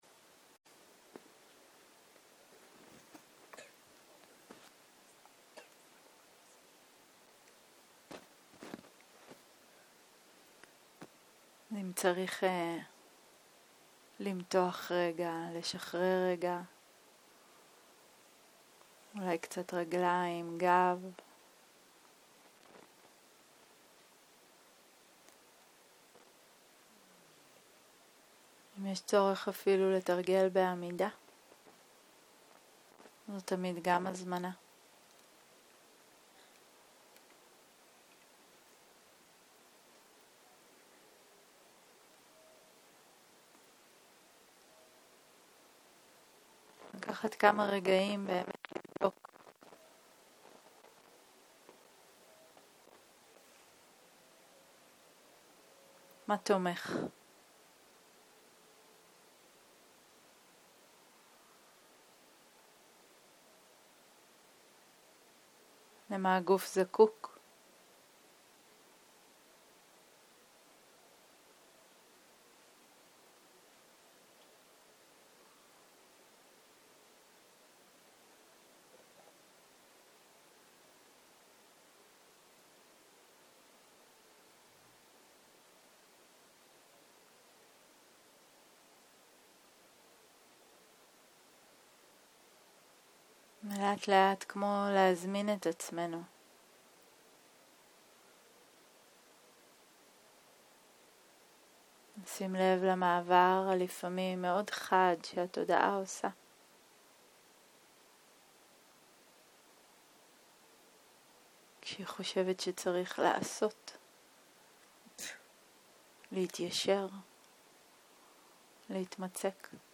לילה - מדיטציה מונחית
Dharma type: Guided meditation שפת ההקלטה